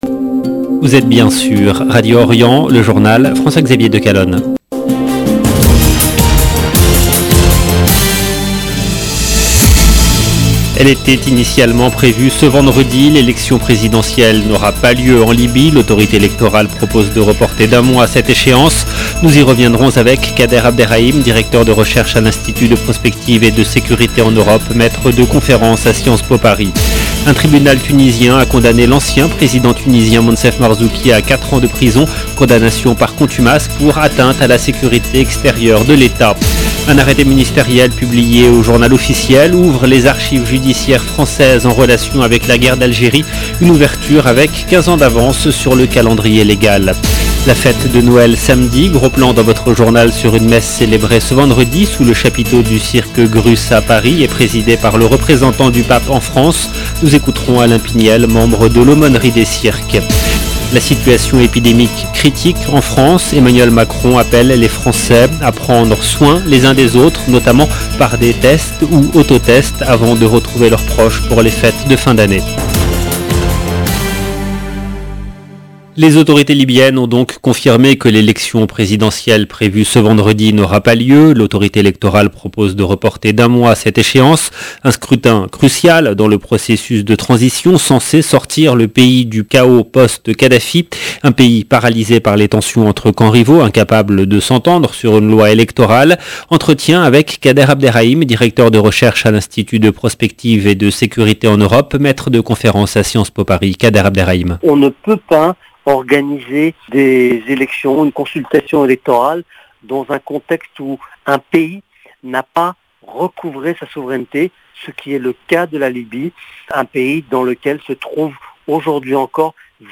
LE JOURNAL DU SOIR EN LANGUE FRANCAISE DU 23/12/2021